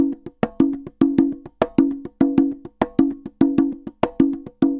Congas_Samba 100_1.wav